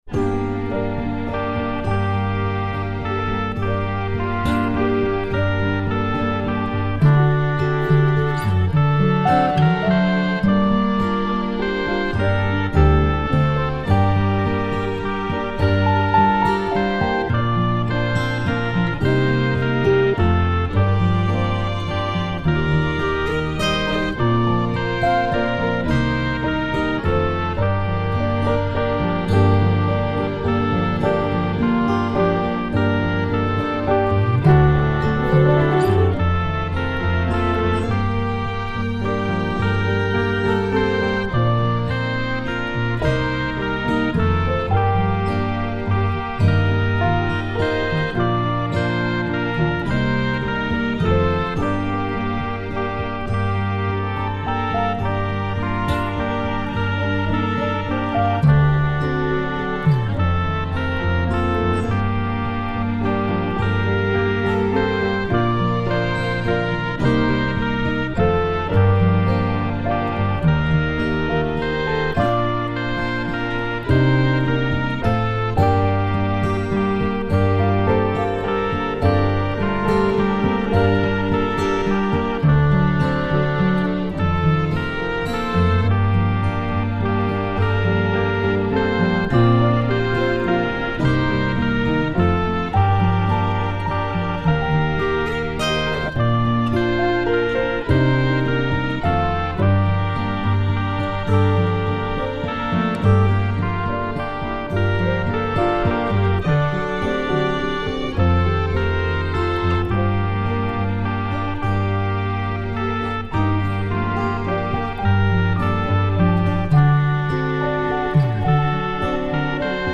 The music is O FILI ET FILLAE – a seventeenth century French tune.
My backing is an attempt to avoid the dodgy church organ VST plug ins and is meant to sound vaguely of the period – such as BIAB can manage.